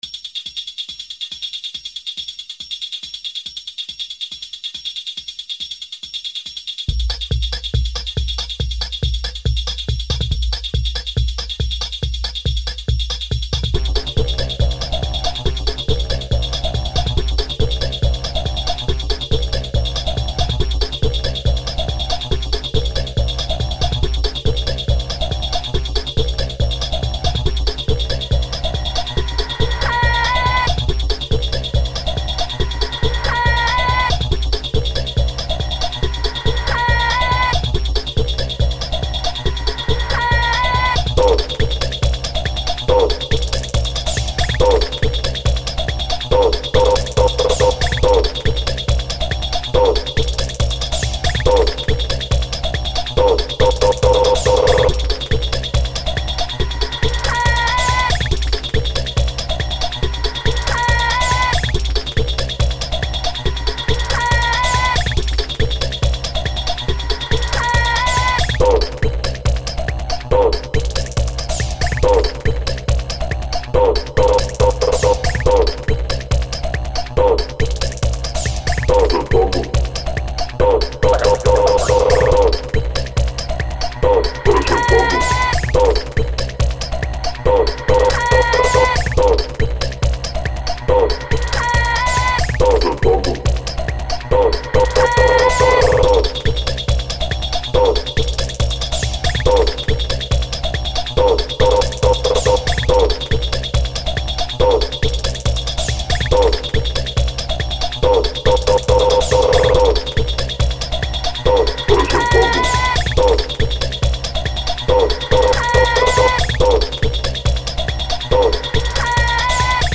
Genre Techno